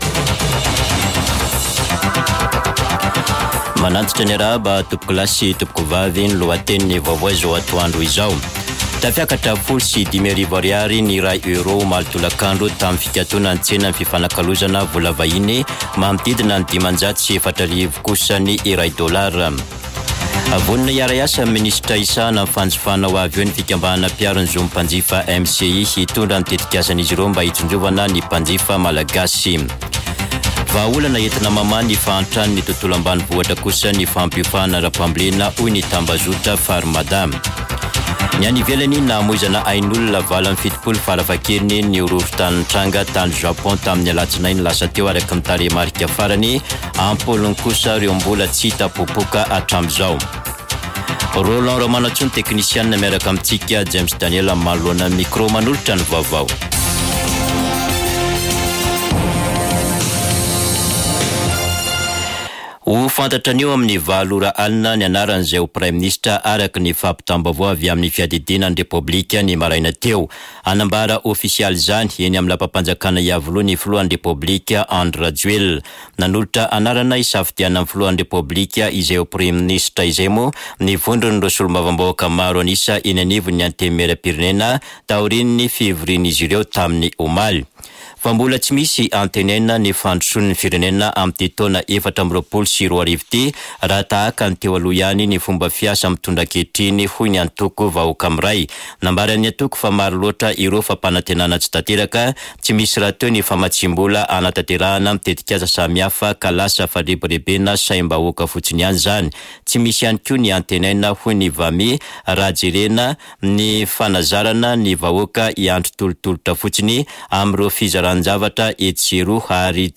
[Vaovao antoandro] Alakamisy 4 janoary 2024